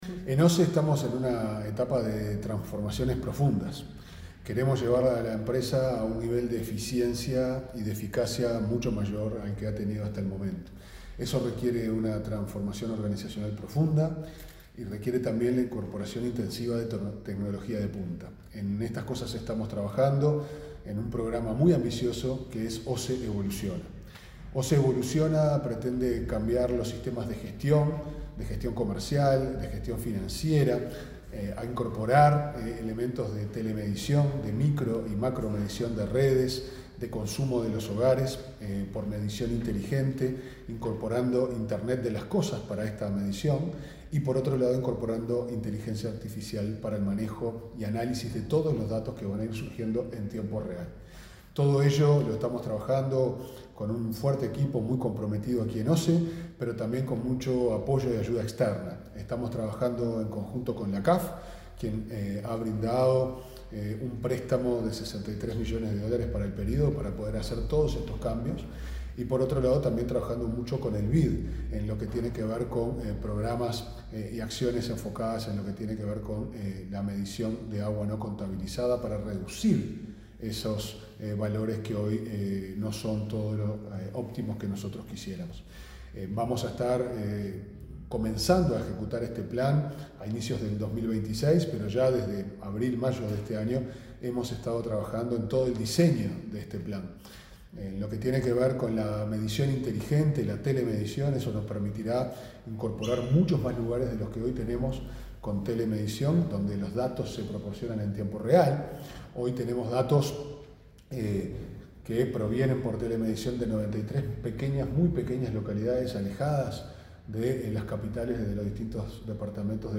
Declaraciones del presidente de OSE, Pablo Ferreri